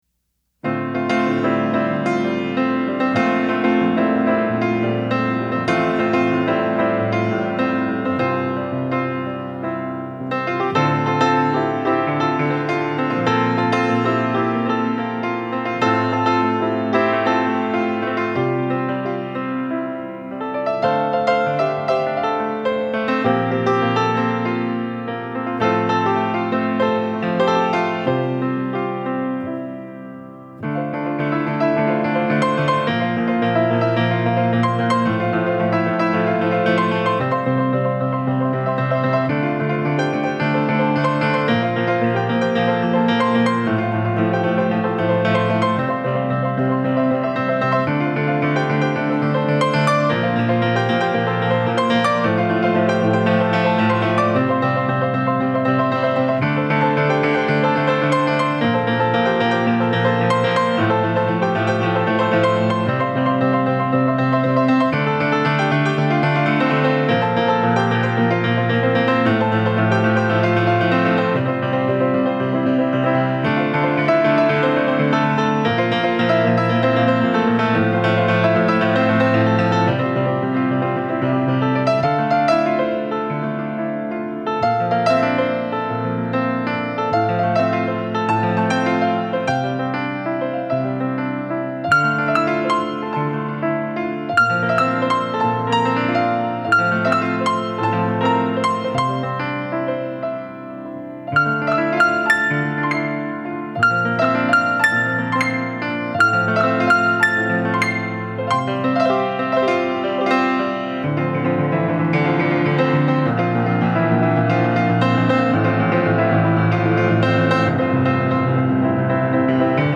Piano Track